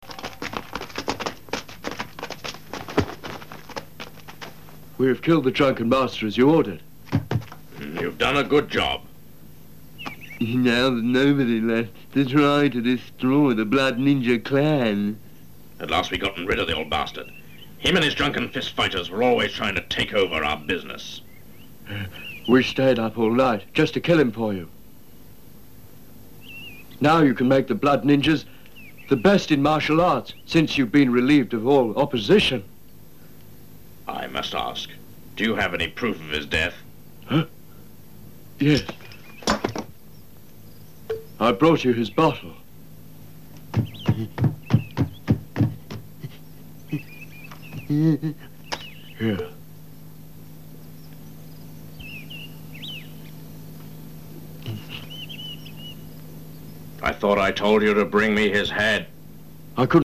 Ah, by the way… I have also recorded a snippet of the beautiful dubbing done in Revenge of the Drunken Master.